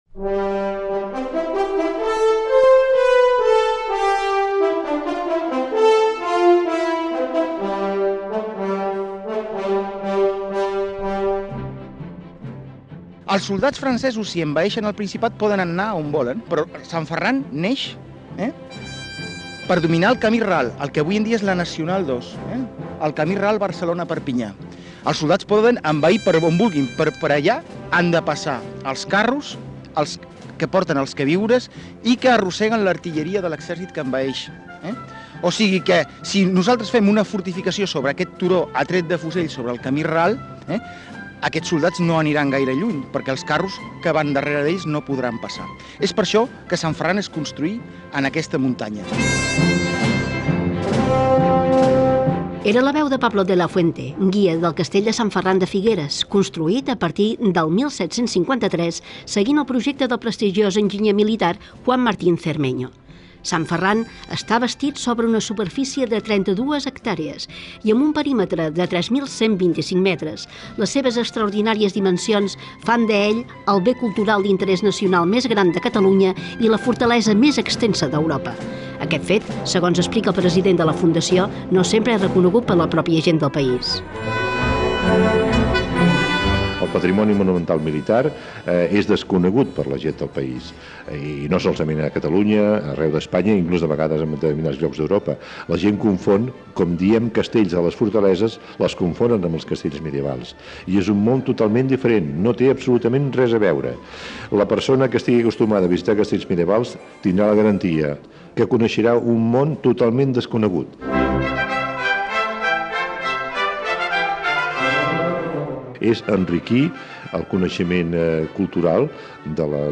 Reportatge sobre el castell del castell de Sant Ferran de Figueres
Cultura